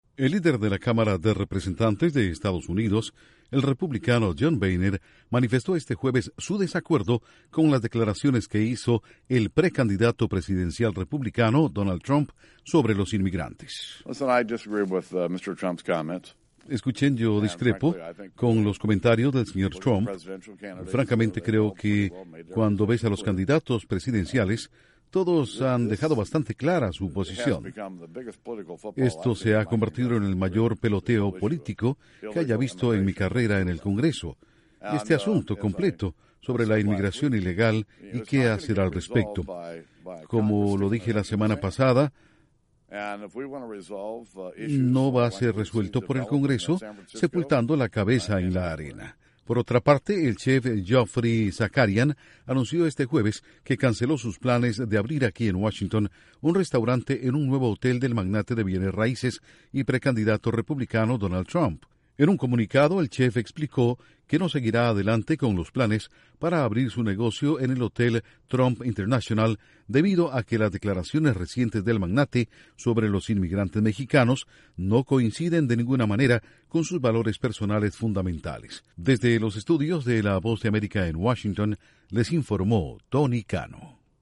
Líder republicano de la Cámara de Representantes expresa su desacuerdo con comentarios de Trump sobre los inmigrantes. Informa desde los estudios de la Voz de América en Washington